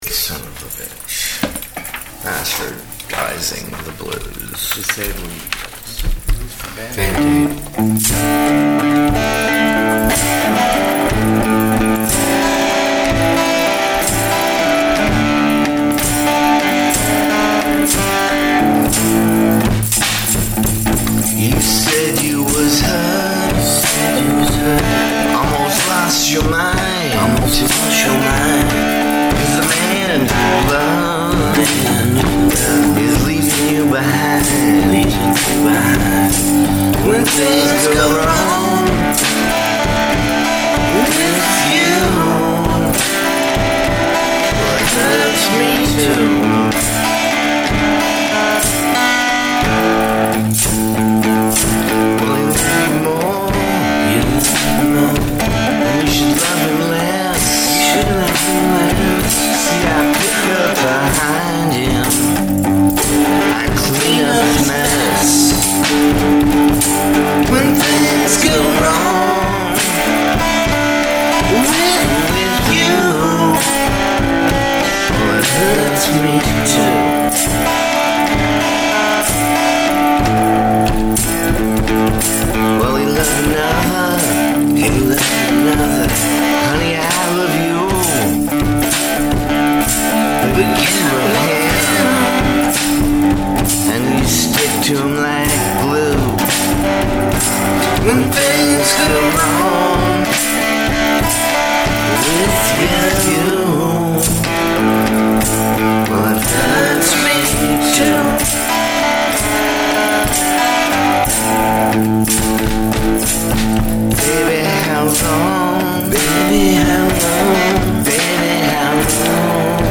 Part of my bastardizing the blues series.